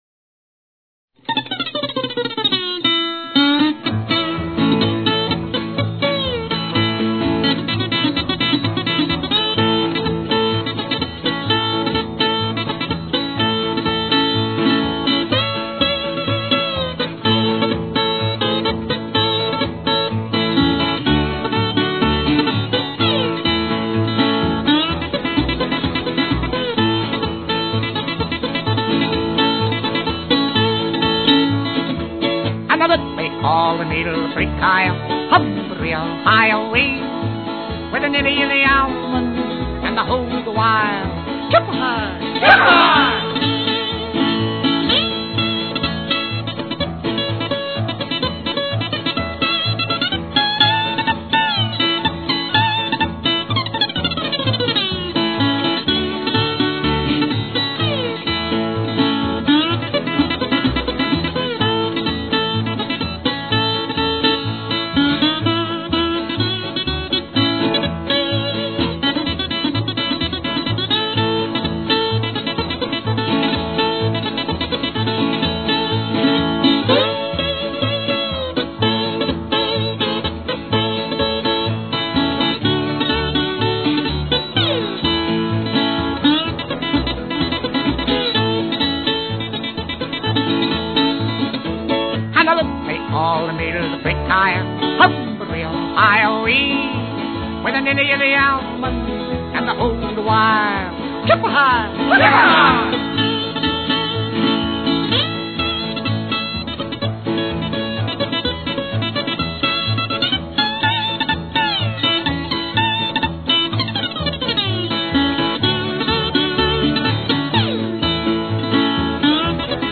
A Tribute To Old Time Country Music